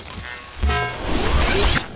bsword1.ogg